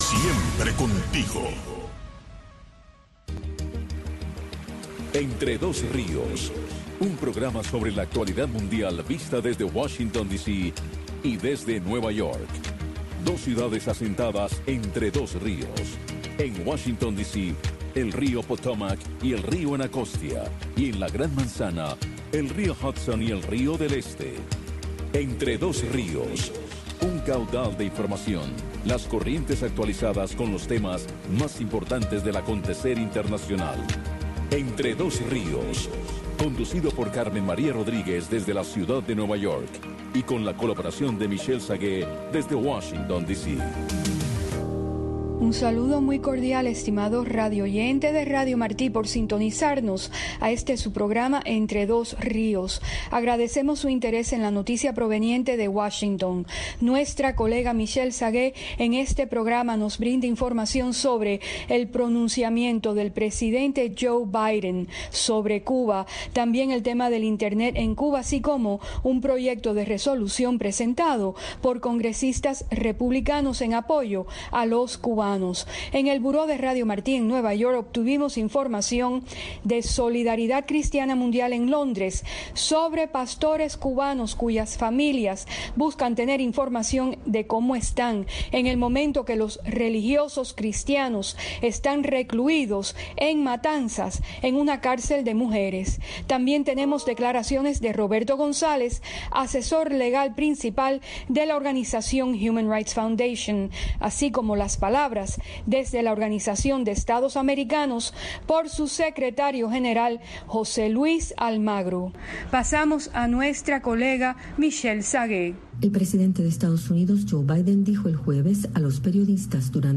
revista de entrevistas